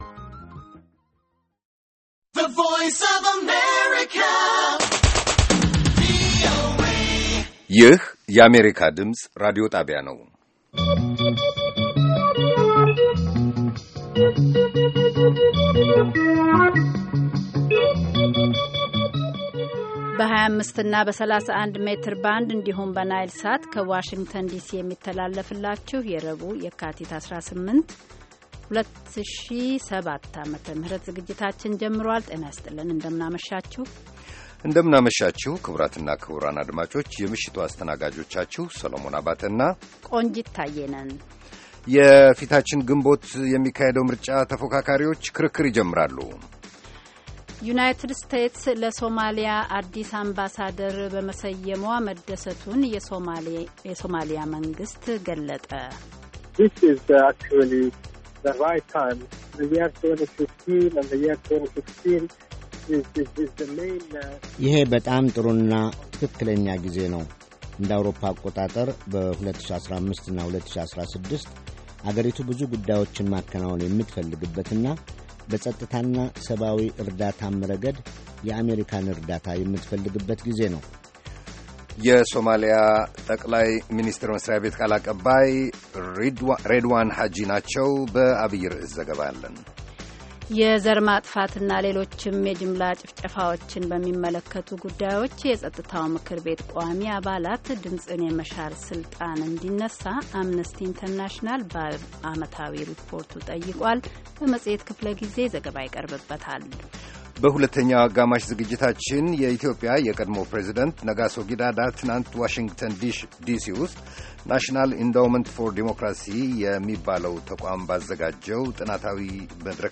ከምሽቱ ሦስት ሰዓት የአማርኛ ዜና